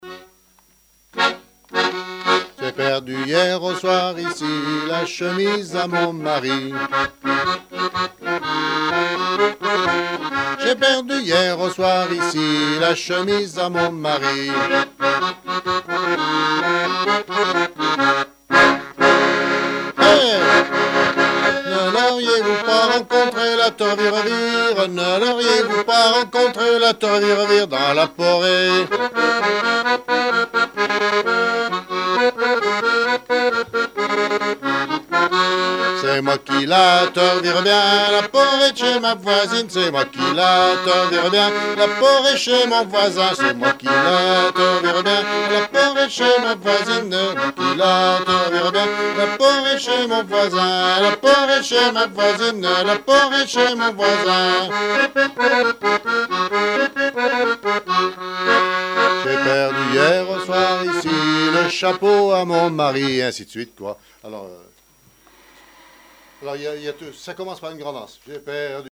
Saint-Christophe-du-Ligneron
danse : ronde : porée
Genre énumérative